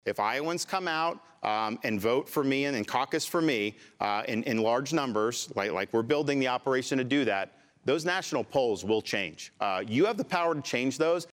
At a FOX News Channel Townhall last night DeSantis responded to a question on his polling by saying this.